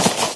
default_snow_footstep.1.ogg